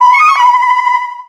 Cri de Meloetta dans Pokémon X et Y.